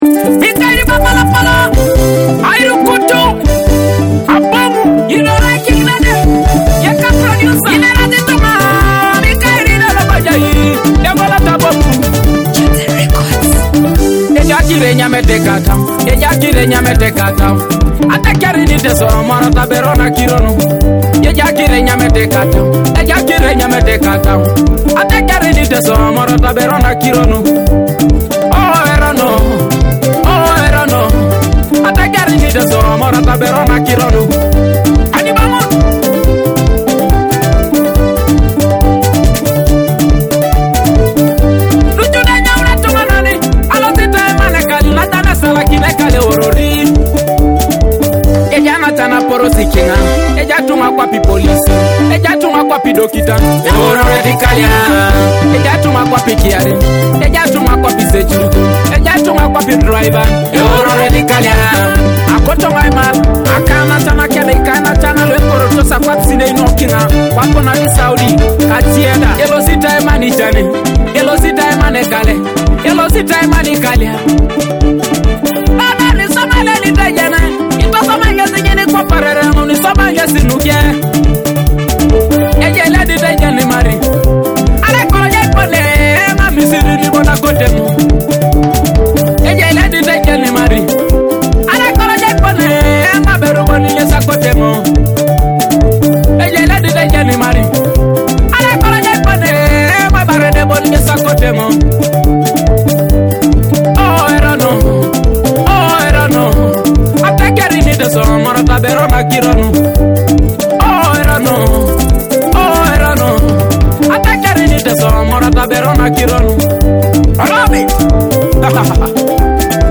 a soulful Teso track full of emotion and culture.